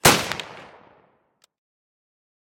努纳维克 " 枪 李恩菲尔德 303步枪射击
描述：枪李恩恩菲尔德303步枪射击